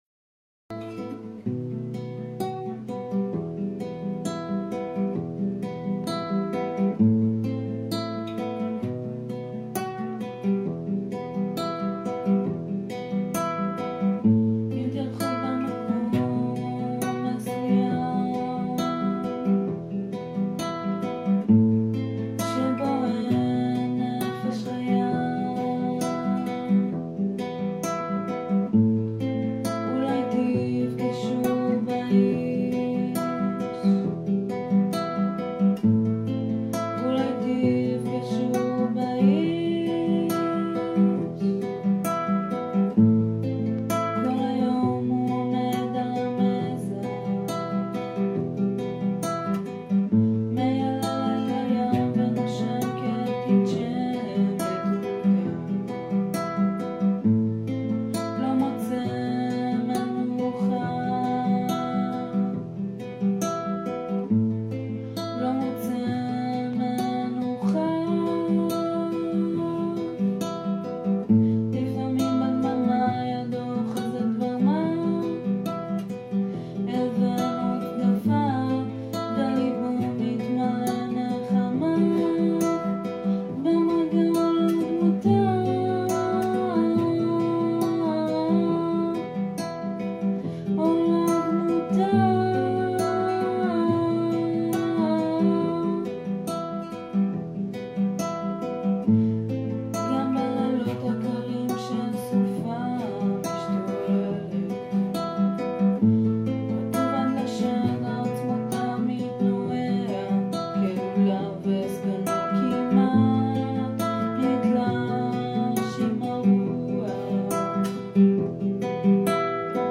איש התנשמת (שירת נשים)